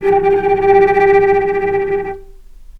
Strings / cello / tremolo
vc_trm-G4-pp.aif